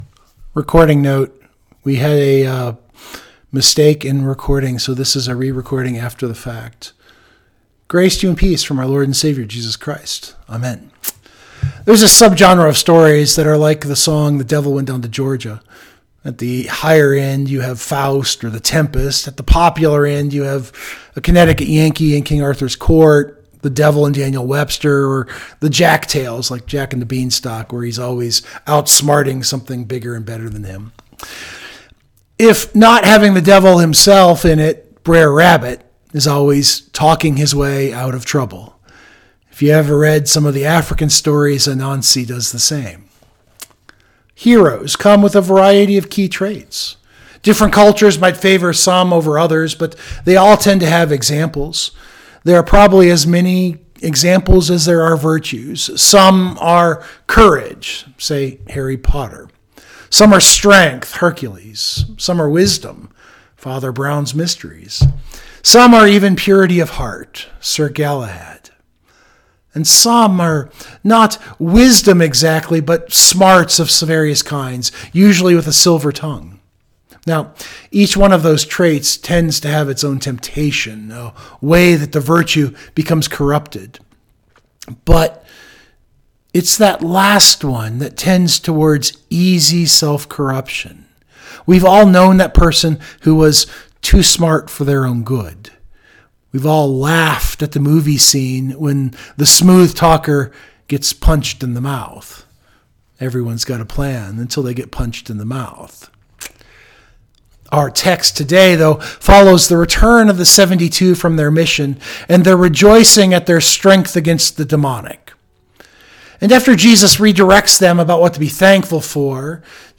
Note: The recording is a re-recording after the fact. We had a recording error real time.